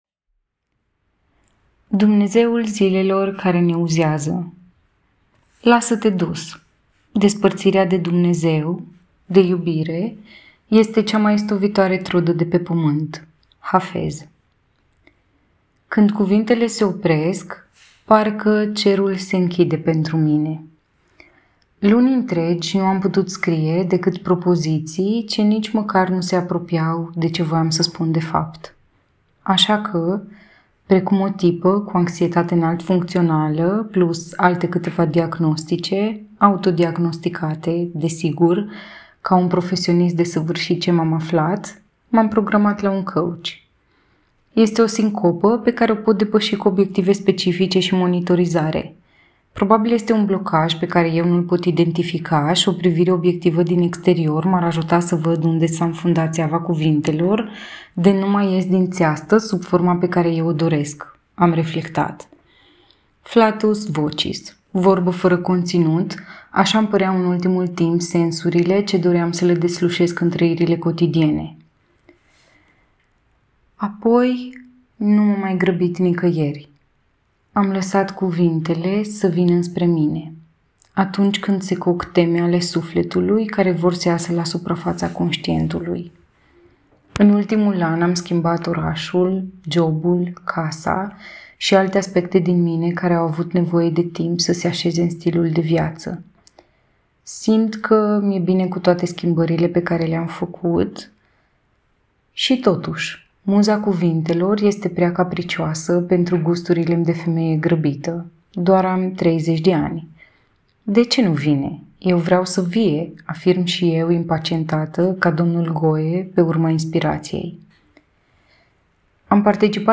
*De mult timp îmi doresc să-mi las amprenta vocală în articole, poți asculta articolul de mai jos în lectura mea.